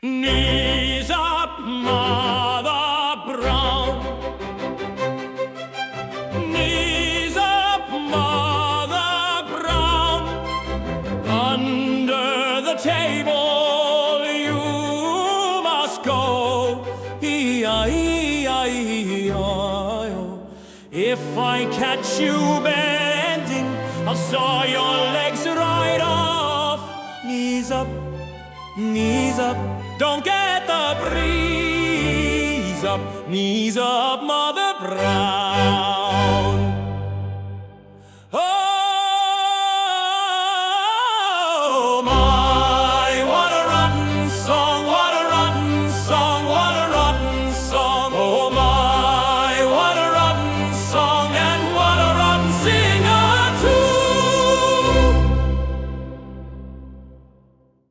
Instead, he has managed to get AI to sing them. There are 2 versions, one operatic and one in popular music style.
🎵 Play/Stop Knees Up Opera